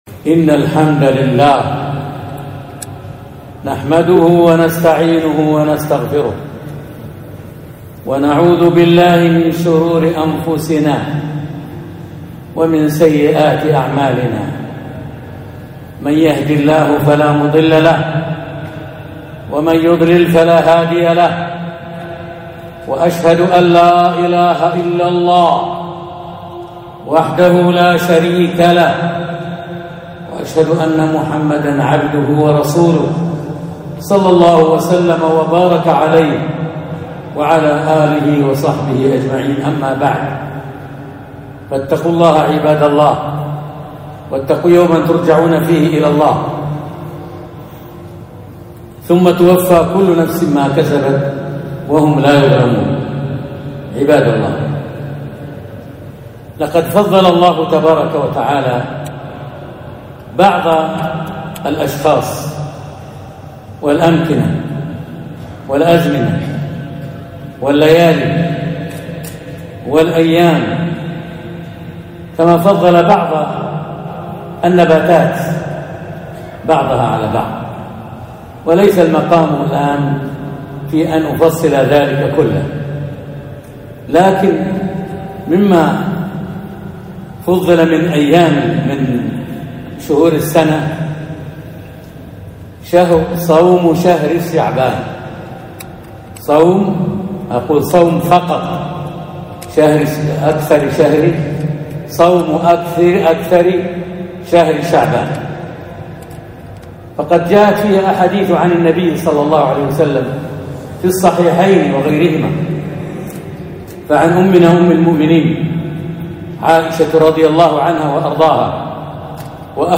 خطبة - فضل صوم أكثر شعبانوو